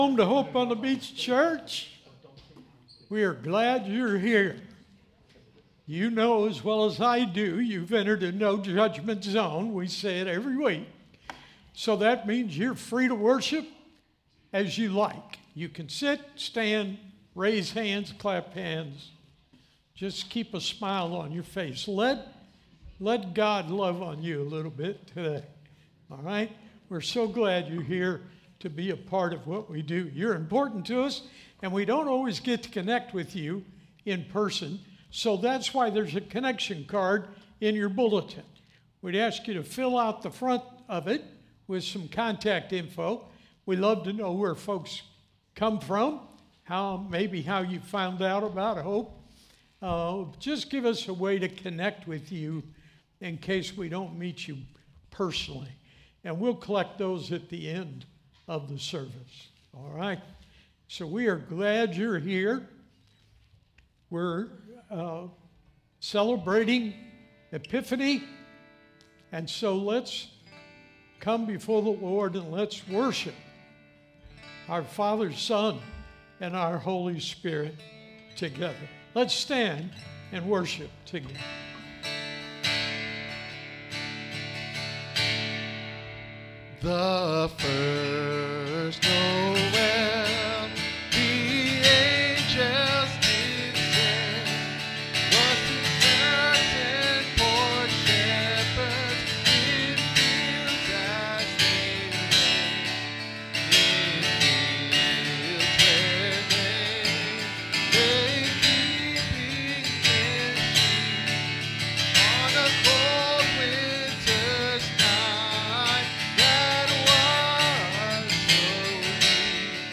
This sermon invites us to see Jesus’ early life as deeply grounded in grace, not pressure or performance.